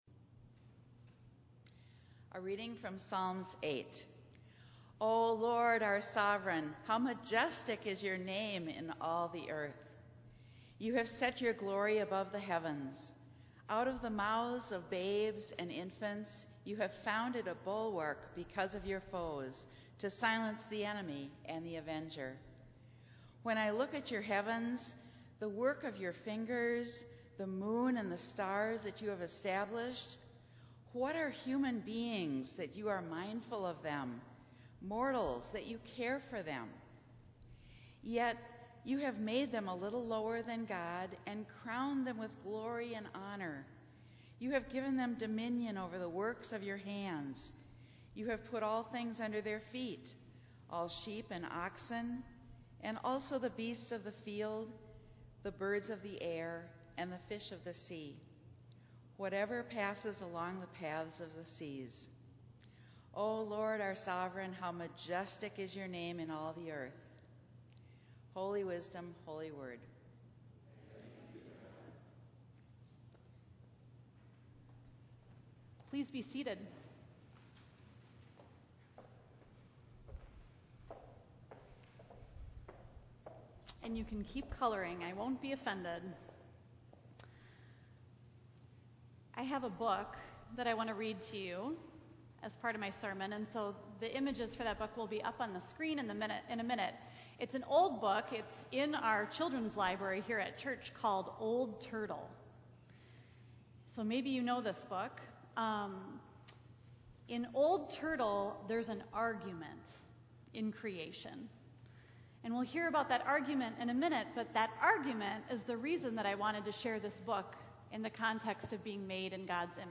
Minnetonka Livestream · Sunday, July 24, 2022 9:30 am